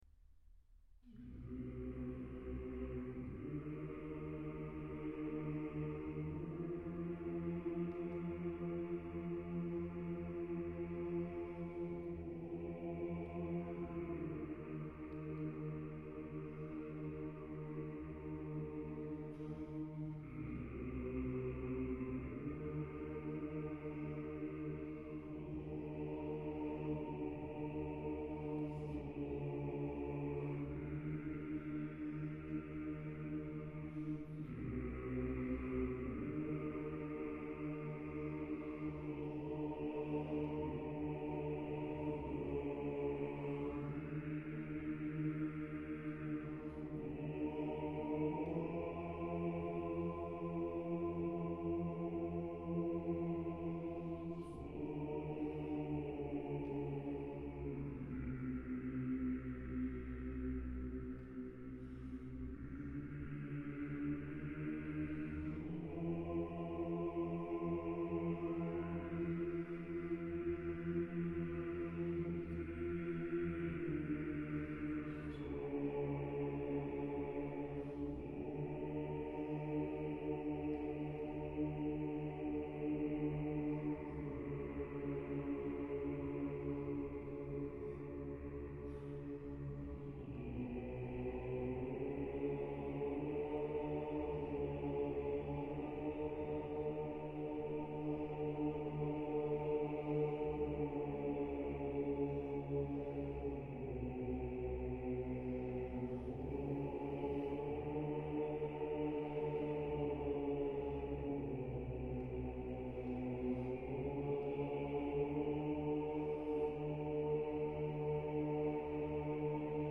А тут Профундо.То есть басы еще на октаву ниже поют.
Ортокс хор.Басы Профундо.